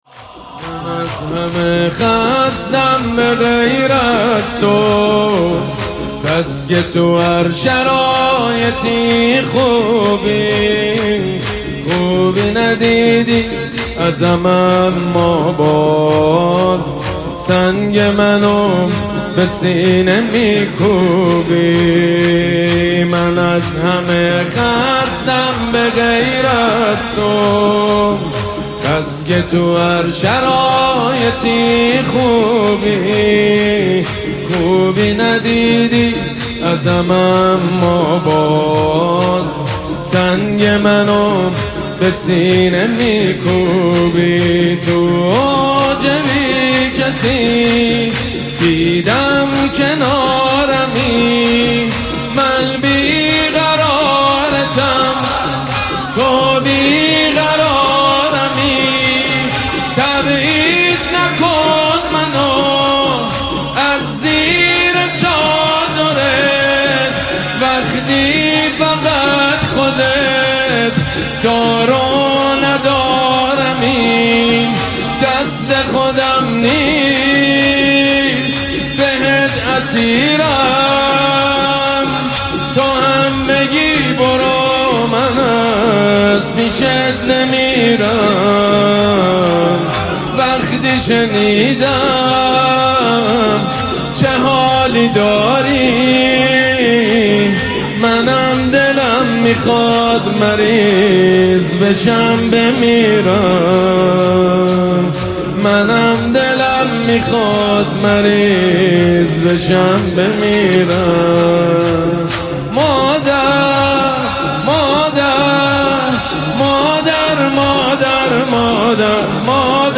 گلچین مداحی حاج سید مجید بنی فاطمه ایام فاطمیه ۱۴۰۲